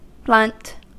Ääntäminen
UK : IPA : /blʌnt/ US : IPA : /blʌnt/